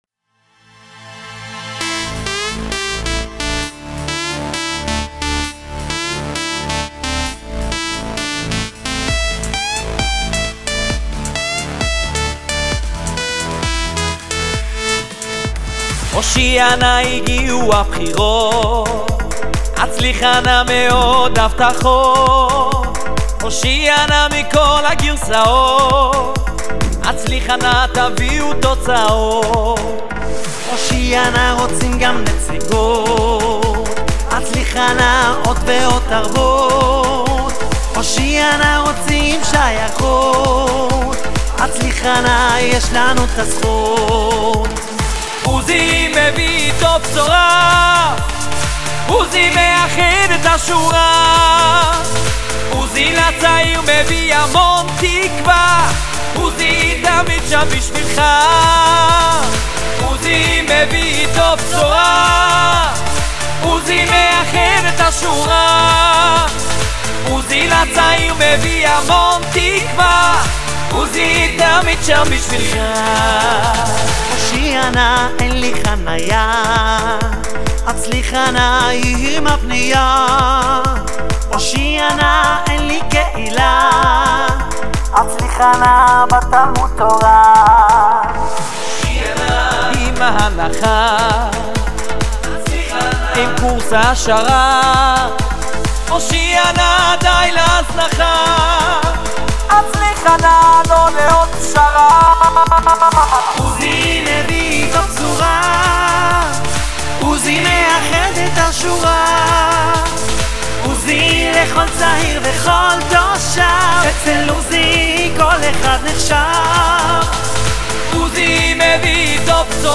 ג’ינגל בחירות תשפד 2024~1.mp3